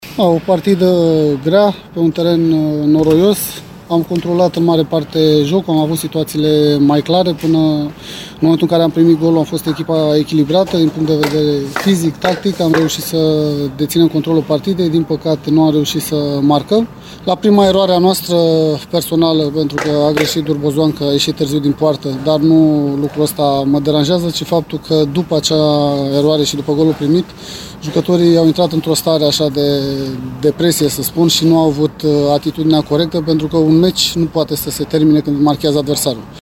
De partea celalată, Ianis Zicu, antrenorul Farului, consideră că, până la primirea golului, echipa sa a condus jocul din toate punctele de vedere şi regretă că elevii săi nu au avut reacţia potrivită, după reuşita gazdelor: